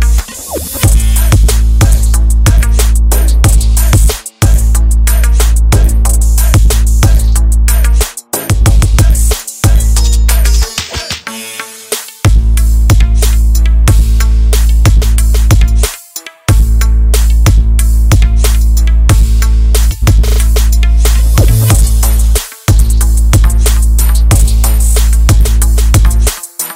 Category: Tabla Ringtones